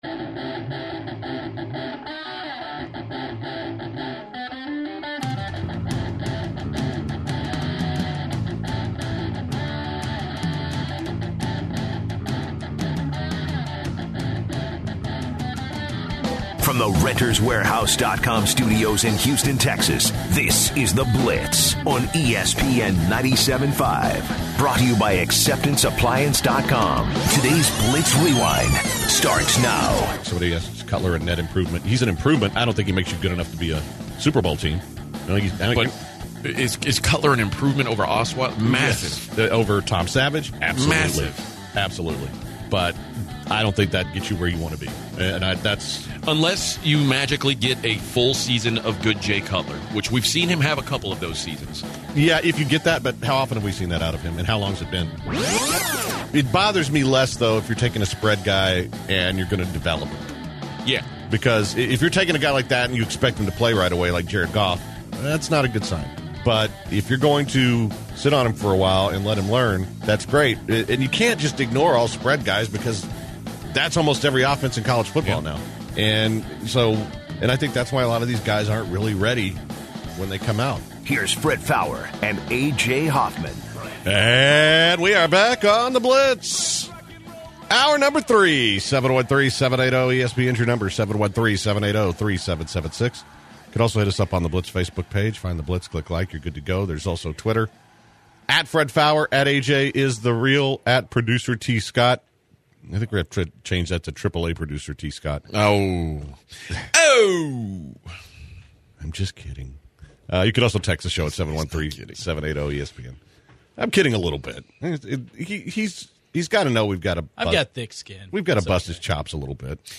In the final hour, the guys take many calls mostly on Tony Romo and the Texans QB situation.